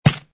fs_ml_stone02.wav